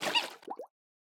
PenguinSplash-002.wav